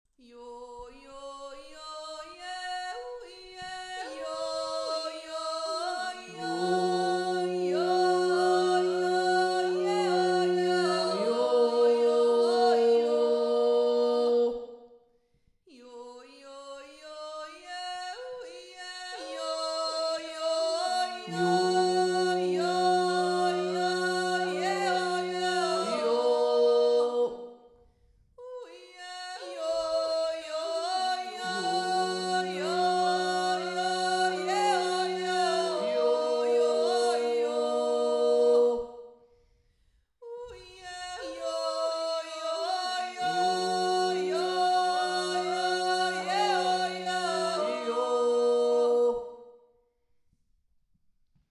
1. und 2. Stimme
Jodler mit Gradheben